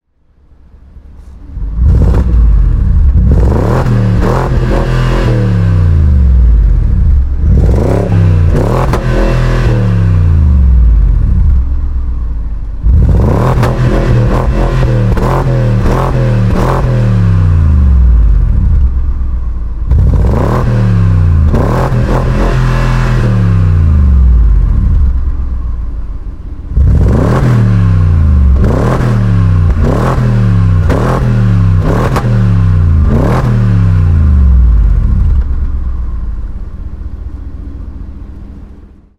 Грохот стрельбы из выхлопа на гоночной трассе